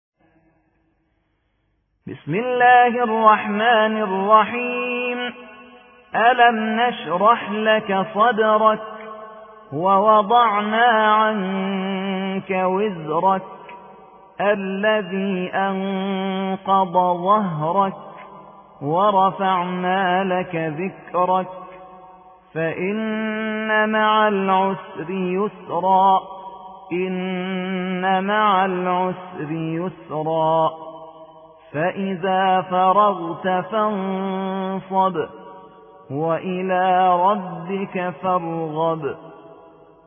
94. سورة الشرح / القارئ